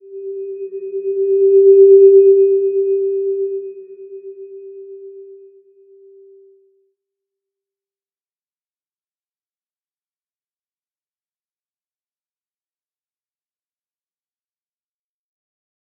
Slow-Distant-Chime-G4-p.wav